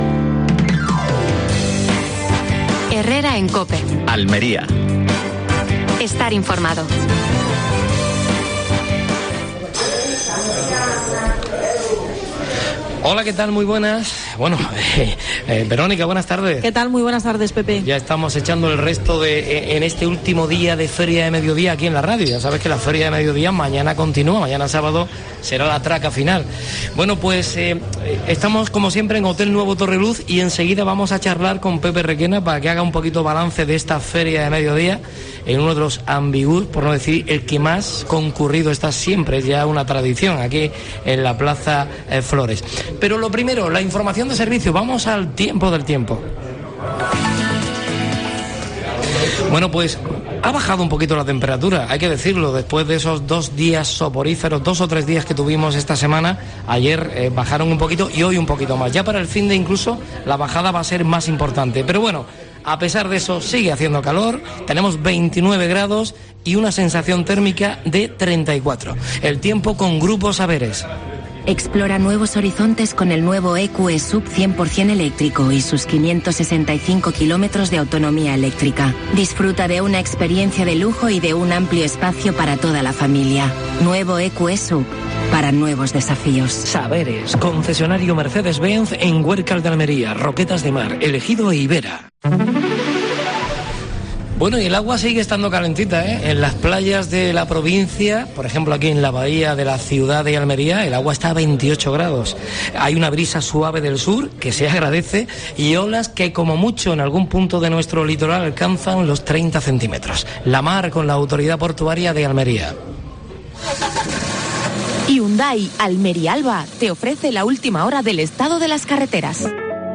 AUDIO: Última hora en Almería. Programa especial de la Feria de Almería. Desde el Hotel Torreluz.